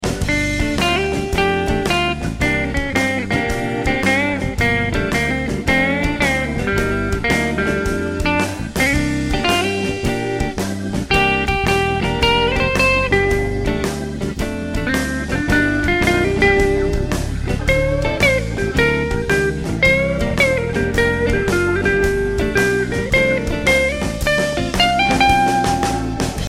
Very rich sounding.
The third clip is a blues shuffle jam track clip done with the Seymour Duncan Seth Lover neck and the GFS Vintage '59 bridge also using the Bluesy Tremolo patch.